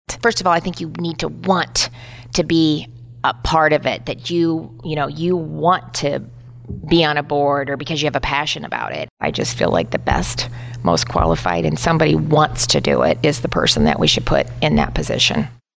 Reynolds made her comments during an interview with Radio Iowa.